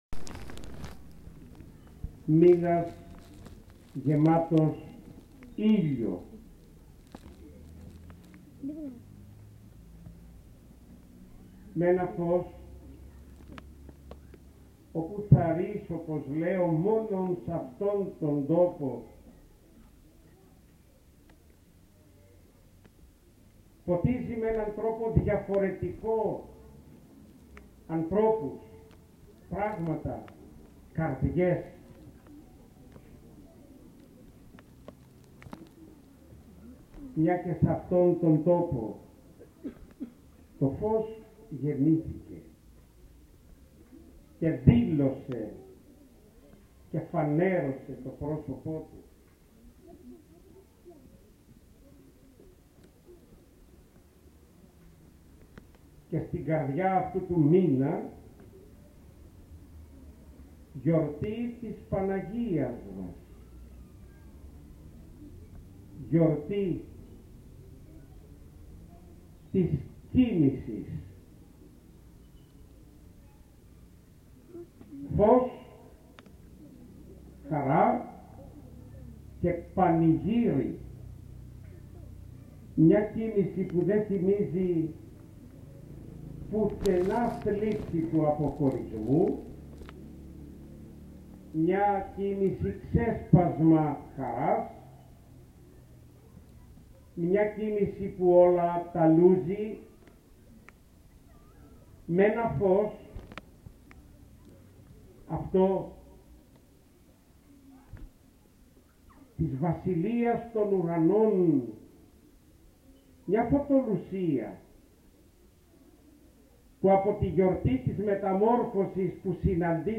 Στο γραφικό παρεκκλήσι της Αγίας Παρασκευής στο Παλαιόκαστρο τελέστηκε την Παρασκευή 12 Αυγούστου 2023 η Ακολουθία του Μικρού Παρακλητικού Κανόνα χοροστατούντος του Σεβασμιωτάτου Μητροπολίτου Νεαπόλεως και Σταυρουπόλεως κ. Βαρνάβα.
Ακολουθεί το ηχητικό αρχείο του κηρύγματος του Σεβασμιωτάτου: